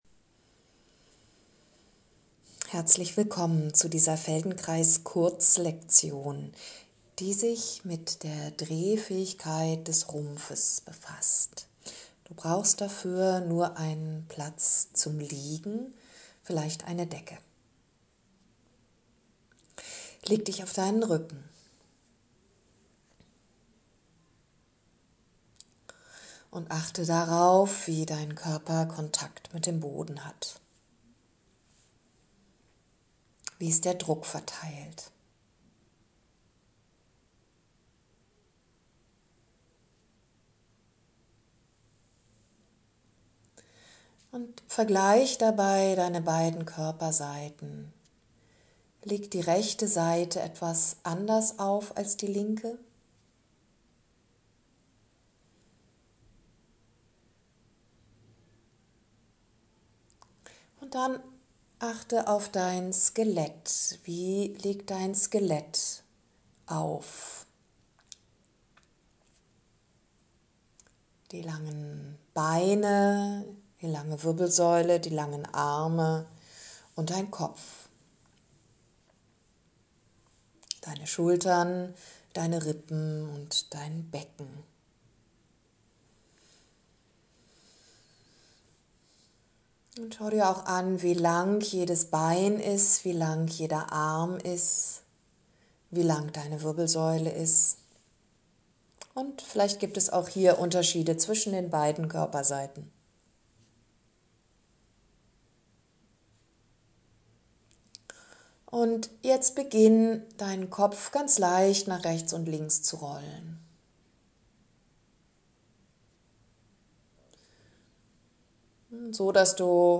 Kurze Feldenkrais -Lektion